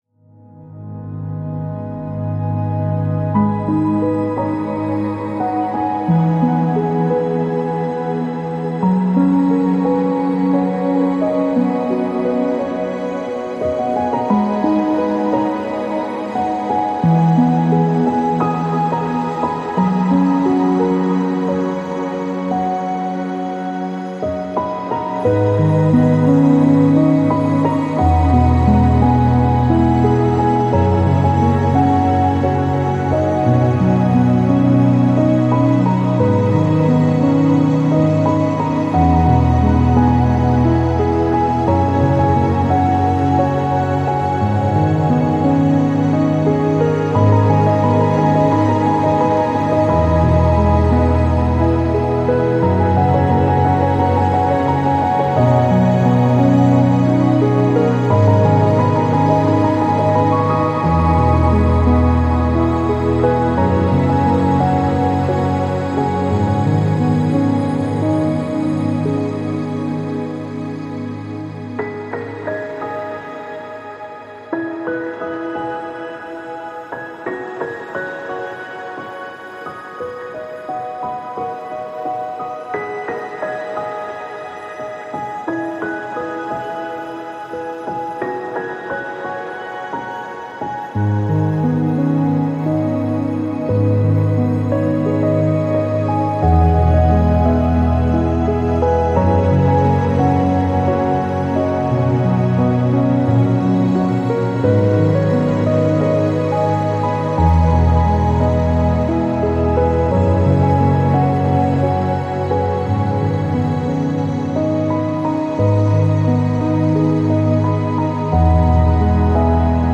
Acoustic
ambient piano